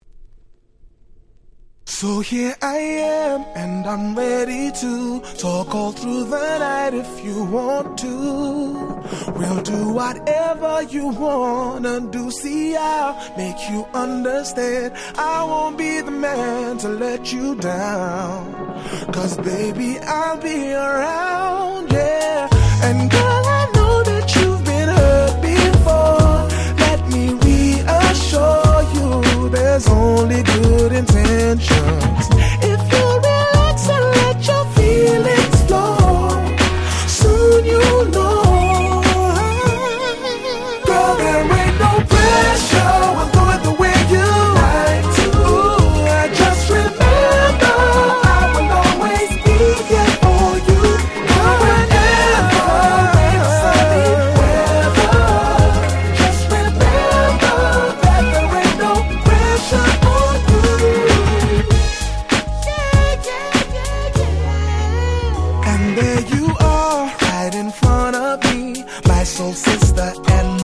03' Very Nice UK R&B EP !!